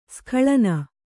♪ skhaḷana